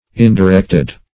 Indirected \In`di*rect"ed\, a.